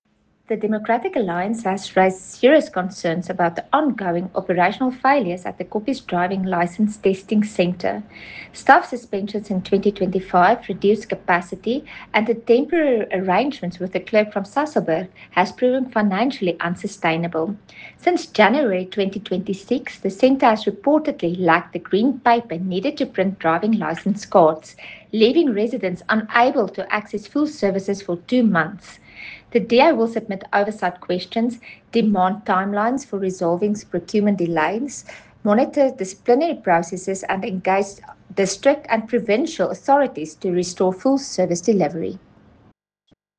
Afrikaans soundbites by Cllr Carina Serfontein and